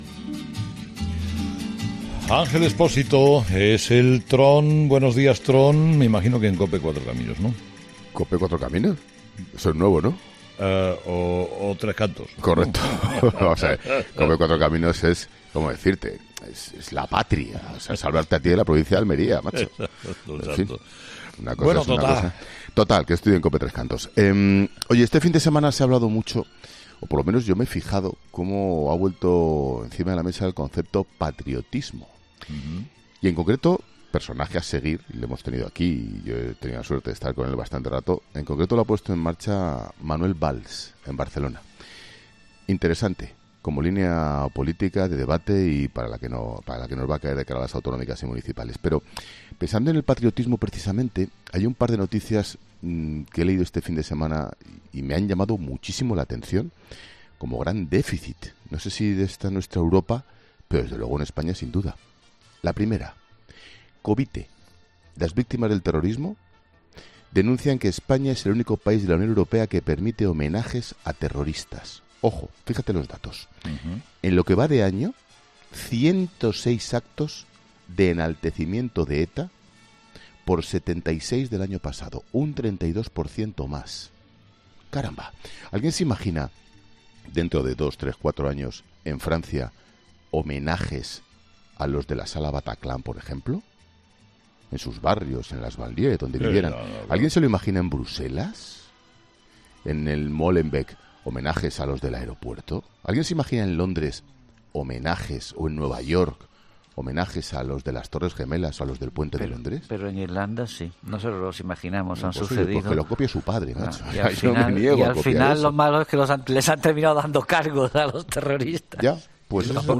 Habla el director de 'La Tarde' del deficit de patriotismo en Europa y de la denuncia de las Asociaciones de Víctimas del terrorismo que advierten de que España es el único país que permite el homenaje a terroristas; 106 actos llevamos este 2018.
Escucha ahora: 'El paseíllo del Tron' con Ángel Expósito en ‘Herrera en COPE’.